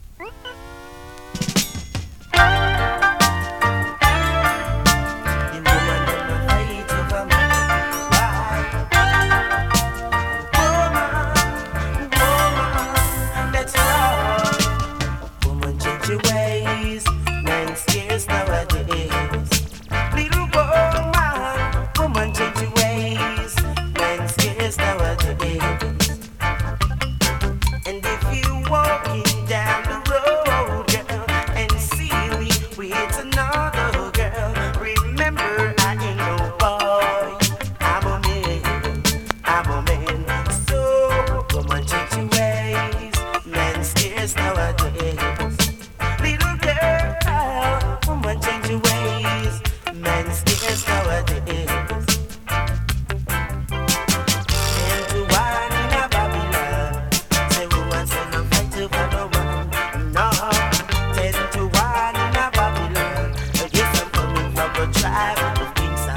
ホーム > 2021 NEW IN!! DANCEHALL!!
スリキズ、ノイズ比較的少なめで